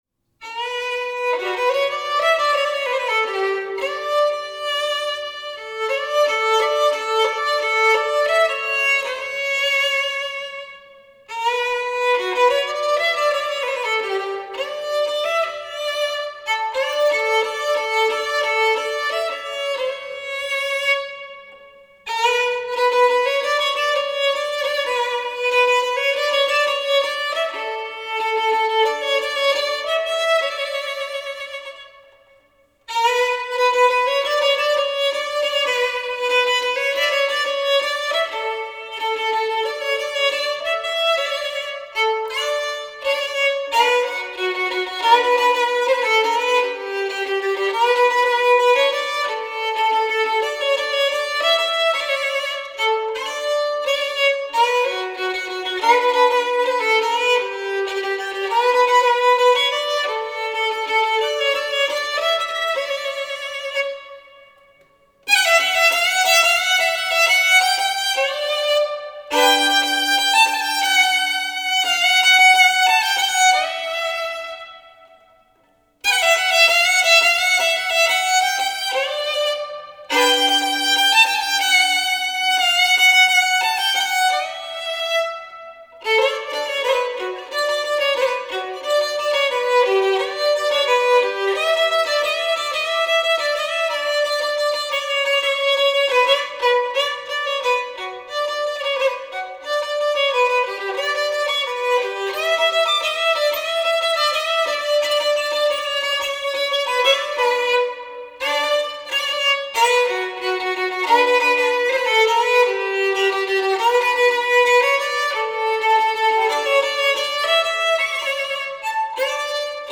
Grabaciones en estudio